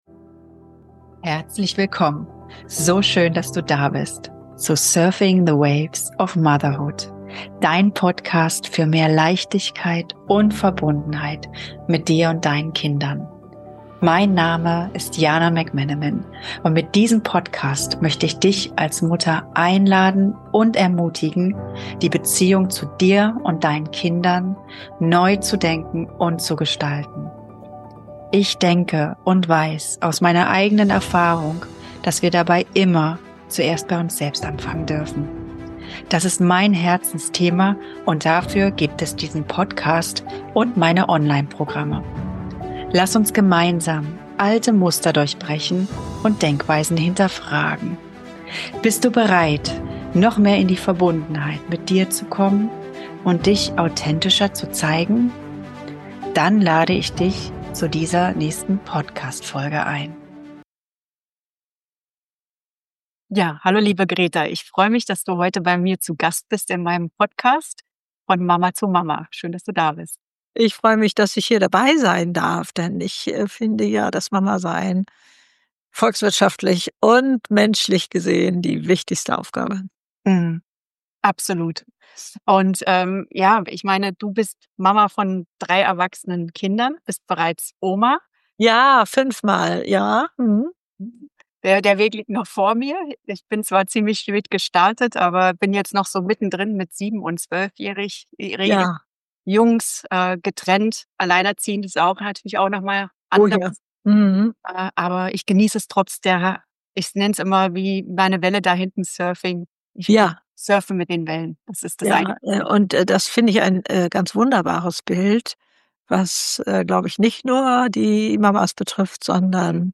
Ein Gespräch, das dein Herz berühren wird.